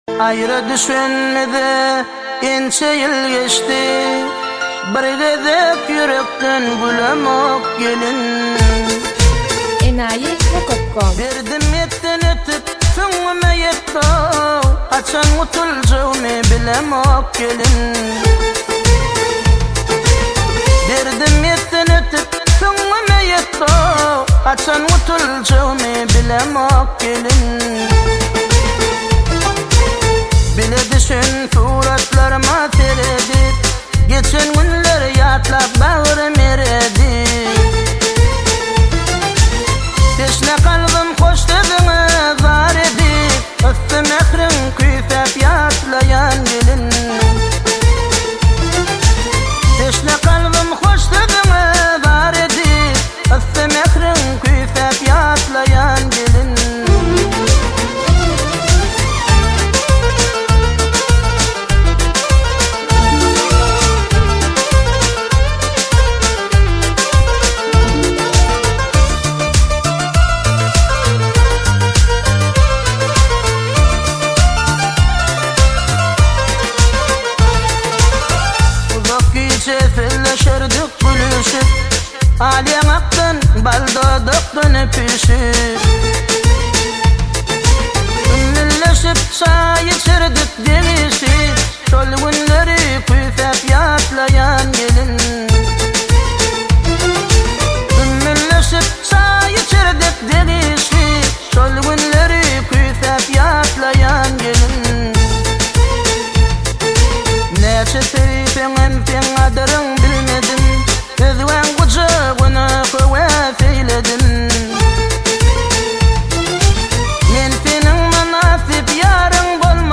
Туркменские песни